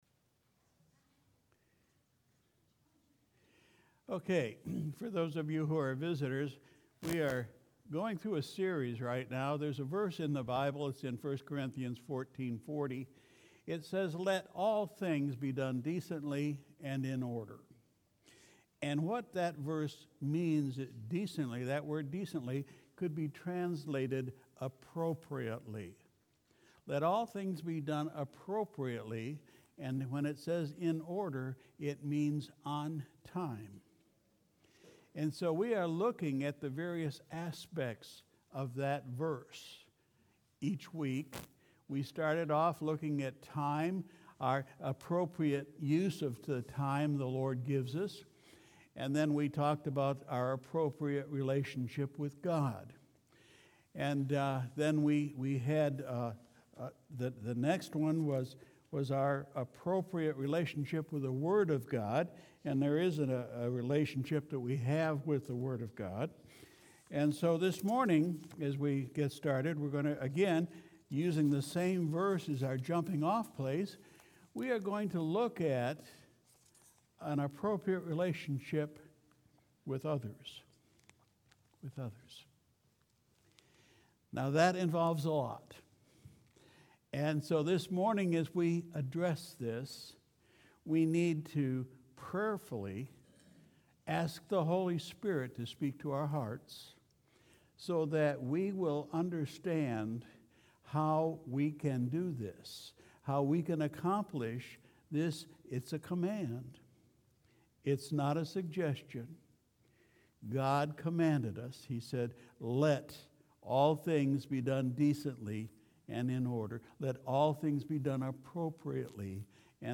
Sunday, March 8, 2020 – Morning Service